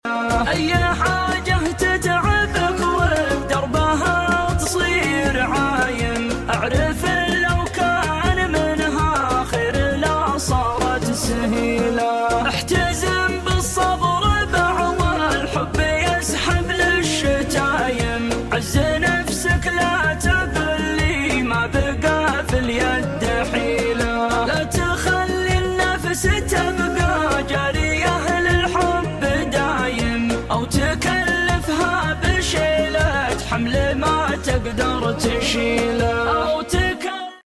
شيلة